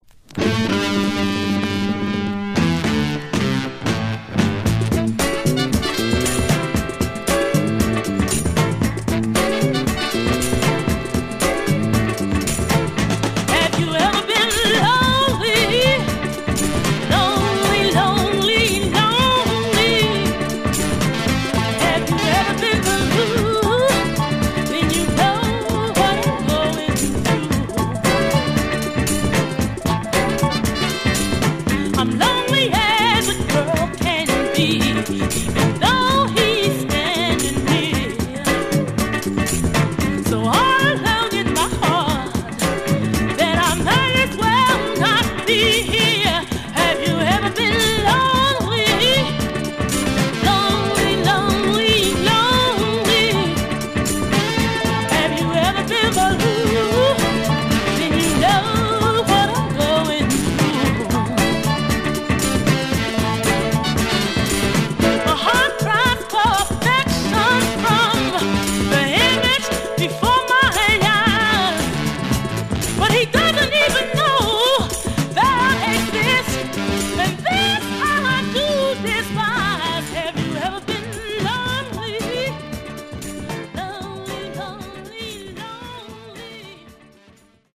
Philly Funk Condition: M- OR VG+ 20.00
Stereo/mono Mono
Soul